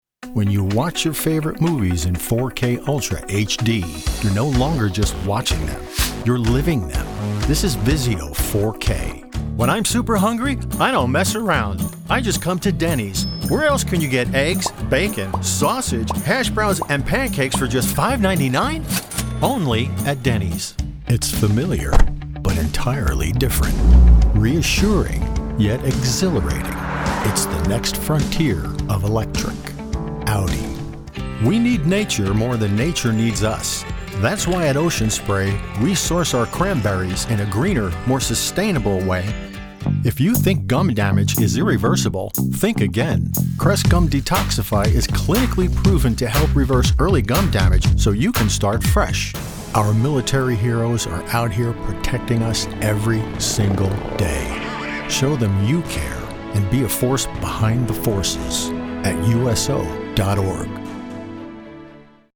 Professional Voice Talent With 5 years experience.
Commercial Demo
North Eastern US NJ, NY, Mass.